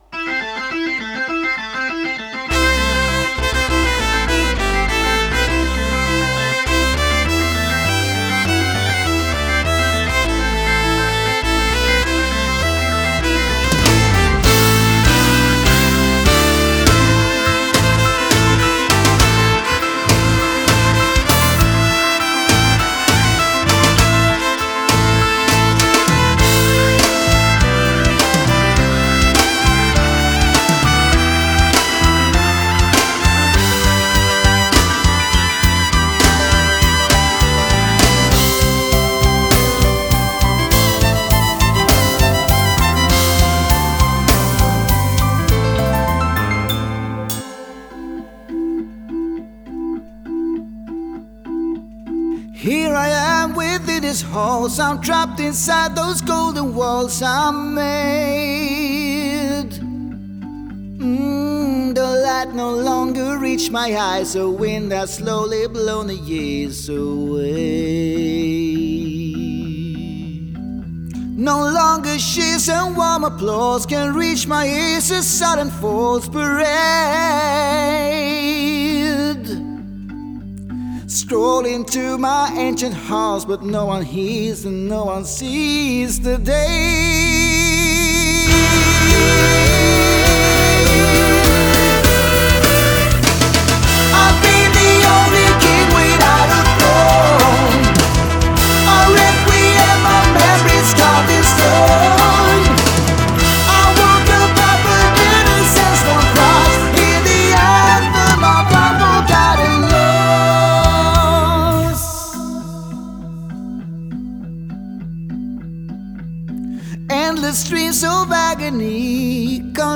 компенсируется скрипкой и клавишами, что, по правде говоря,